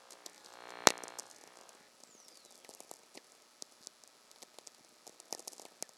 Archived Whistler Event Data for 2024-05-12 Forest, VA USA